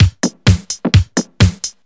Electrohouse Loop 128 BPM (6).wav